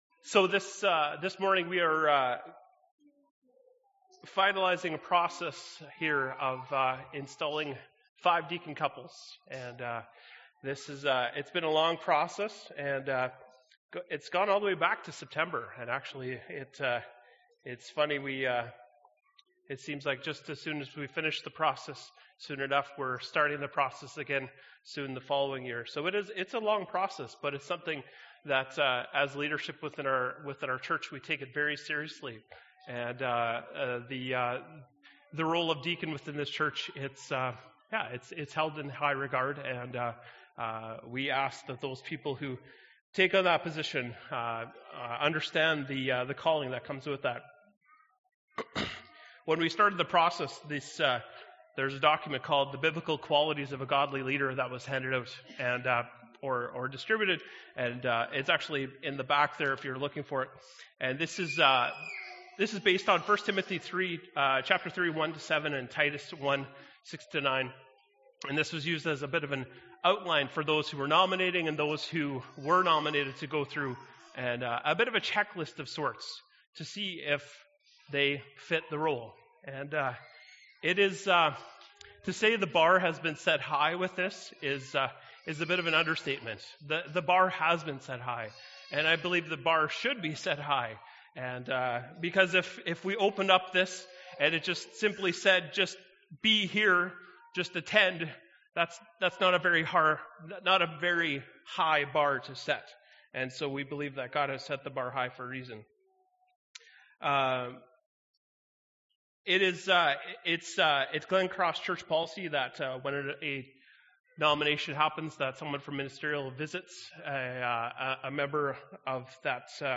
Jan. 17, 2016 – Sermon